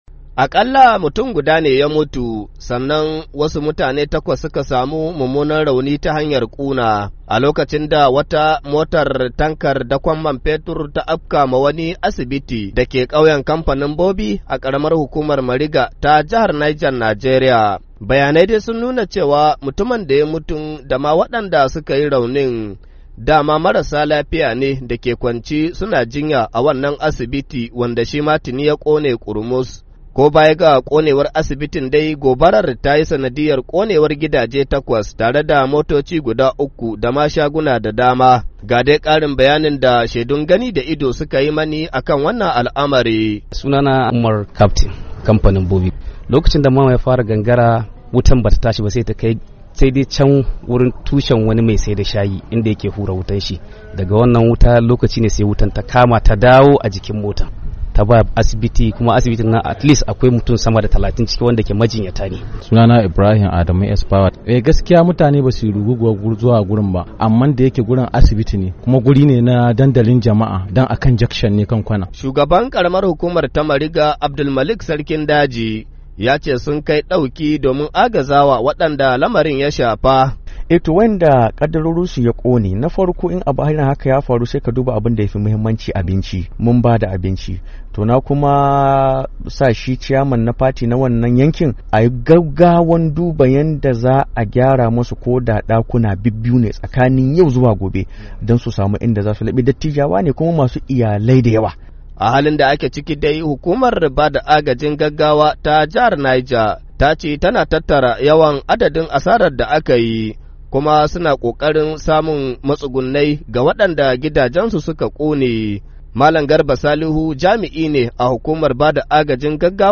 Labari da Dumi-Duminsa